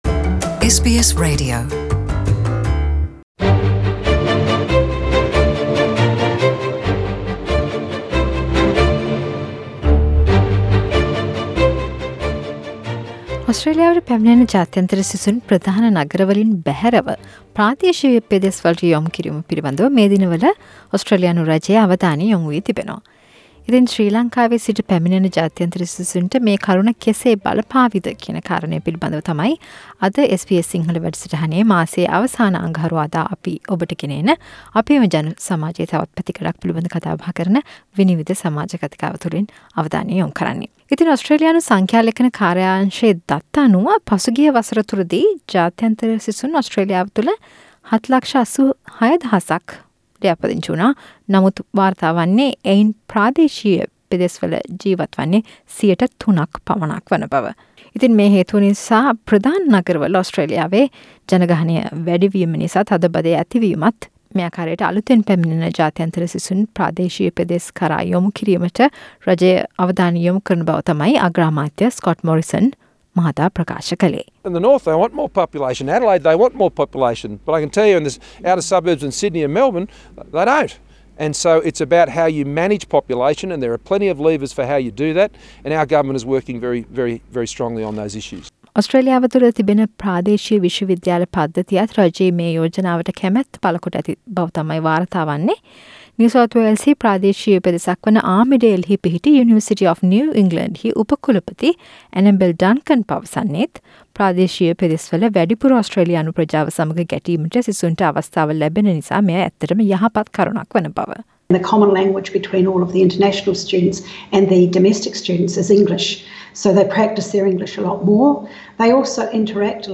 SBS සිංහල මාසික සමාජ කතිකාව